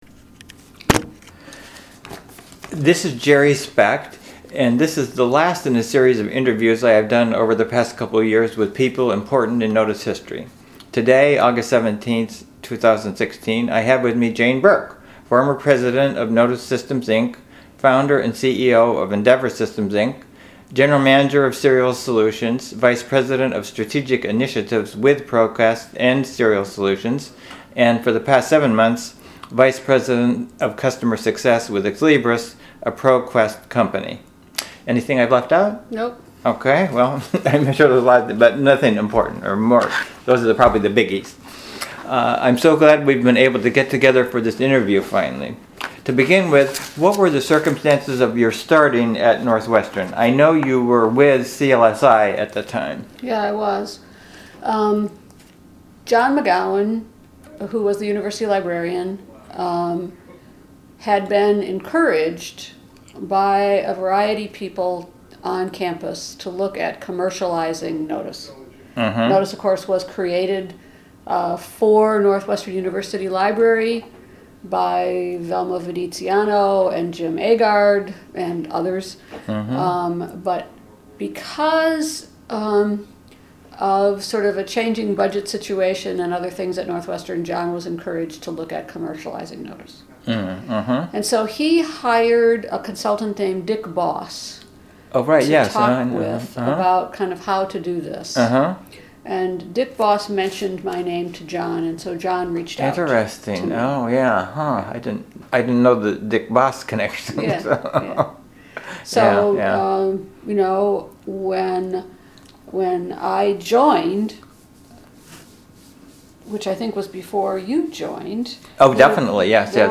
Link to complete unedited (50-minute) audio recording of interview�